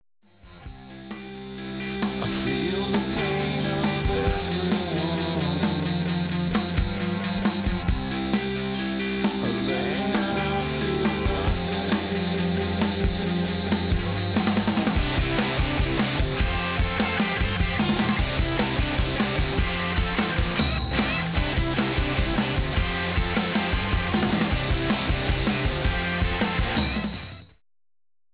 vocals, guitars, drums, keyboards
bass
Format: College/Alternative